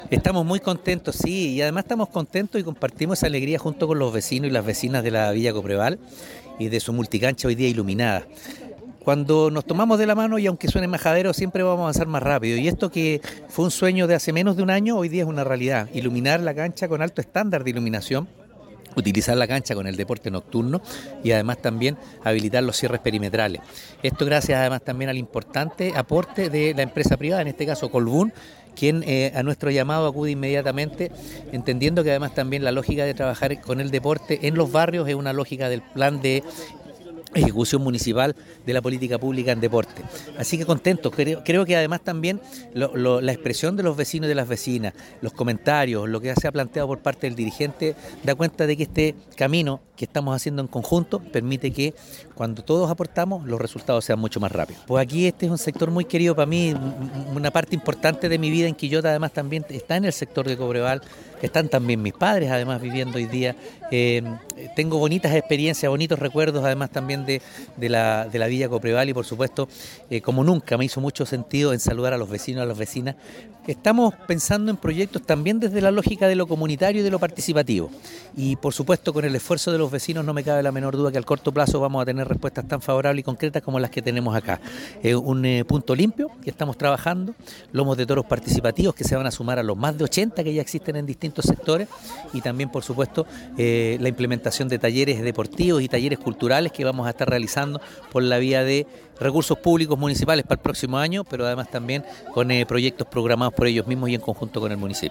Alcalde-Oscar-Calderon-iluminacion-de-cancha-villa-coopreval.mp3